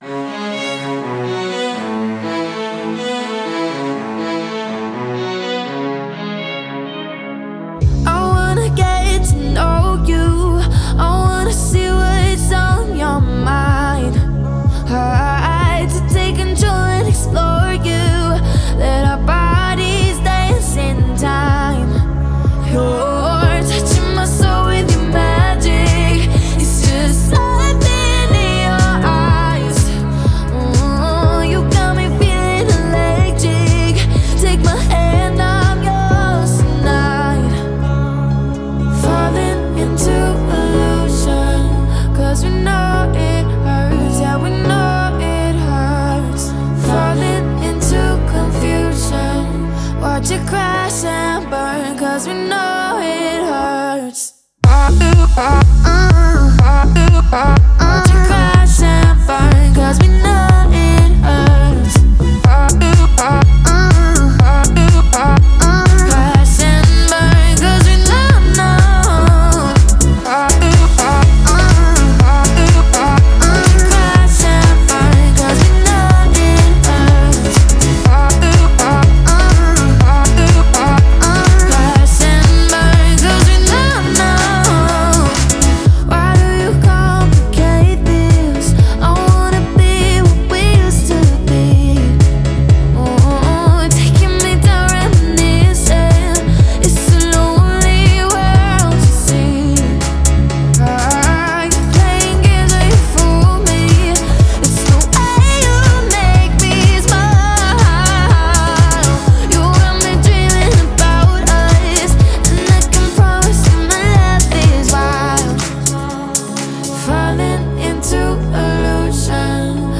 это динамичная композиция в жанре EDM